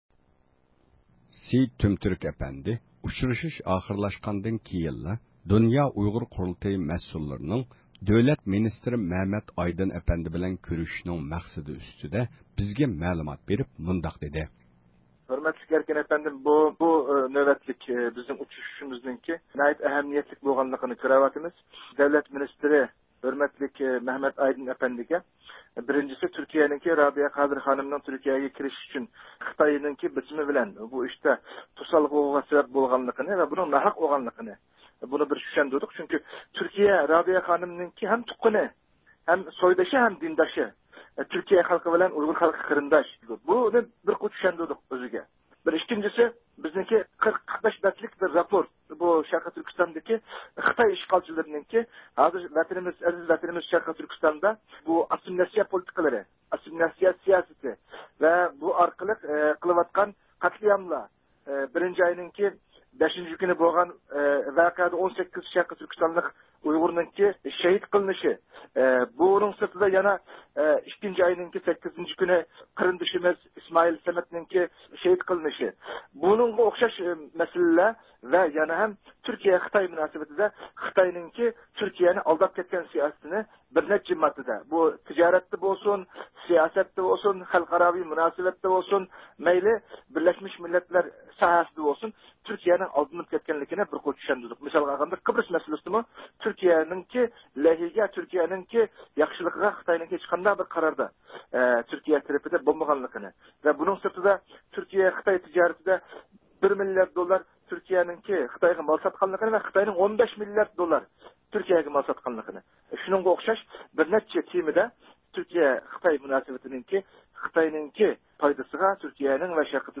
بىز بۇ ئۇچرىشىش ئاخىرلاشقاندىن كېيىن، دۆلەت مىنىستىرى مەمەت ئايدىنغا سۇئال سورىدۇق.